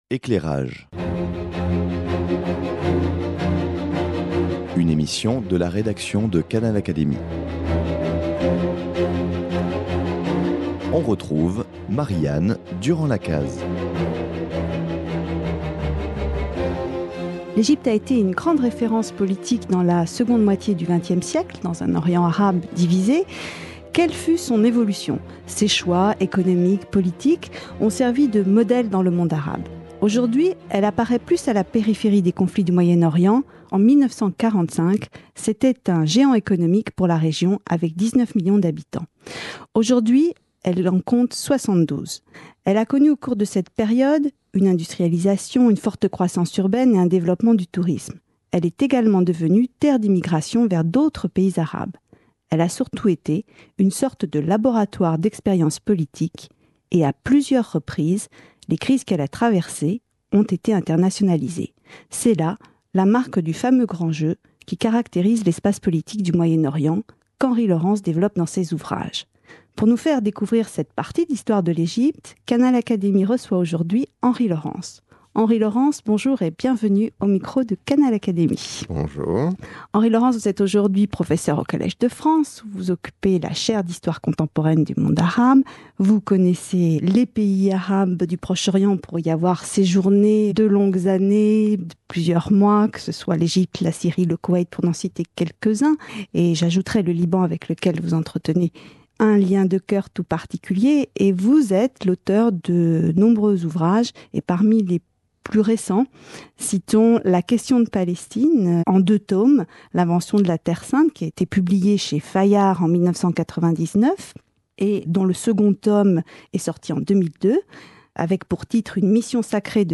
Henry Laurens, historien du monde arabe contemporain, retrace l’histoire de l’Egypte de 1945 à nos jours. Quelles sont les évolutions politiques, économiques et sociales de l’Egypte de la seconde moitié du XXe siècle ?